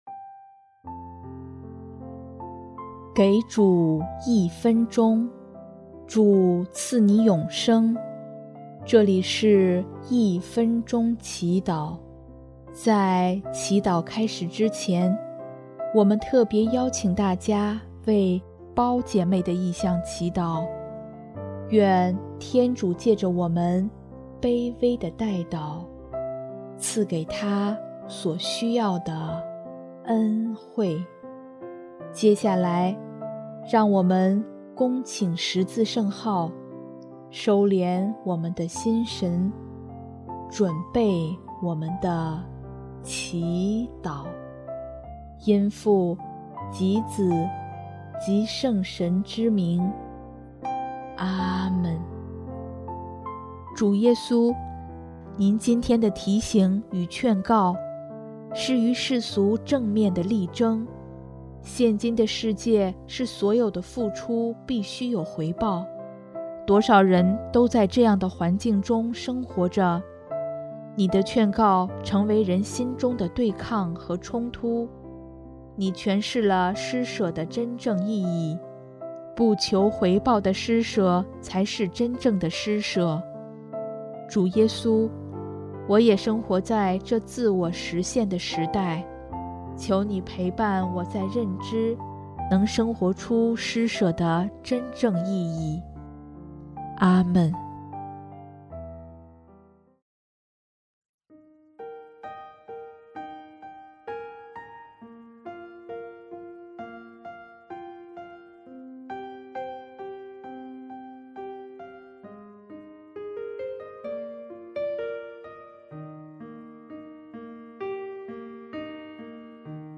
【一分钟祈祷】|11月3日 回报的施舍